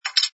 sfx_put_down_beercap02.wav